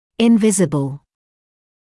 [ɪn’vɪzəbl][ин’визэбл]невидимый; неразличимый